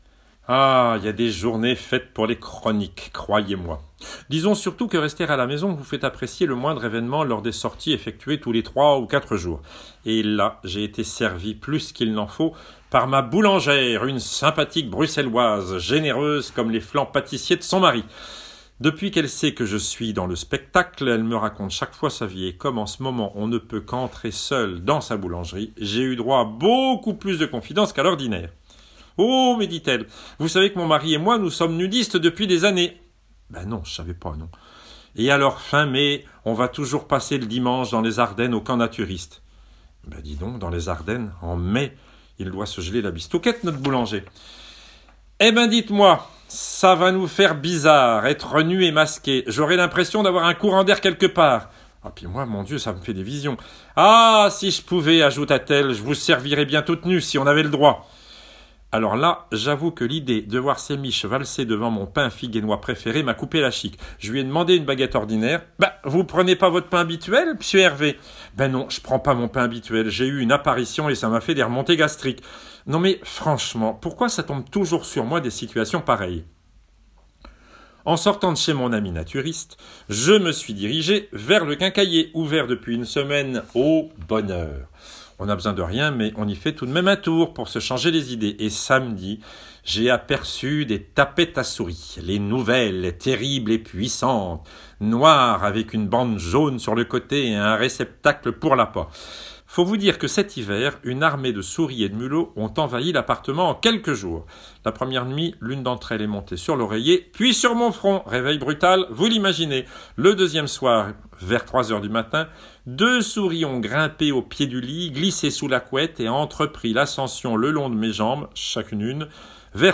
Hervé Niquet – jadis si occupé – nous livre chaque semaine sa chronique du confinement, depuis son appartement où seule la lumière lui tient compagnie. Cette semaine, le Maestro évoque ses relations avec sa boulangère nudiste, l’envahissement de son appartement par des souris sournoises, les frasques du chien Pepper et – bien sûr – les mesures absurdes prises pour les artistes Coronavirés.